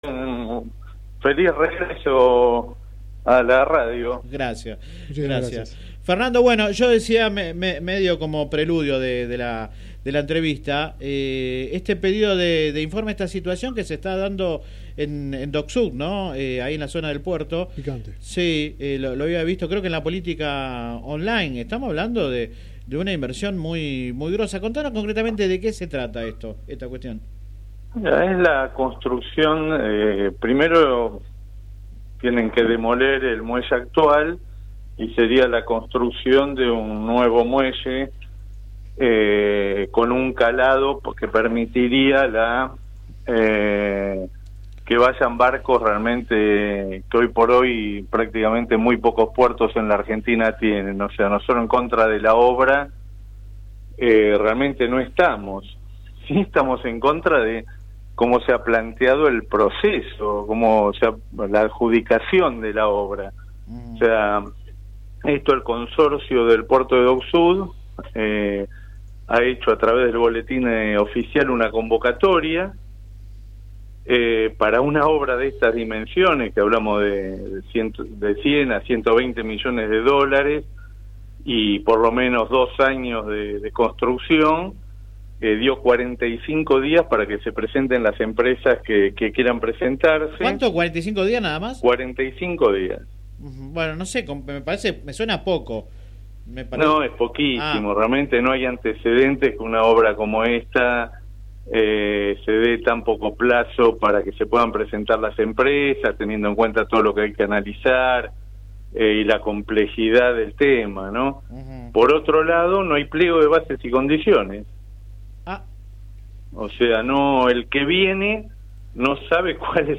Apuntó a la falta de seguridad y cargó duro contra el secretario del área. El dirigente radical habló en el programa radial Sin Retorno (lunes a viernes de 10 a 13 por GPS El Camino FM 90 .7 y AM 1260) sobre su futuro político y se metió en la interna de la UCR.
Click acá entrevista radial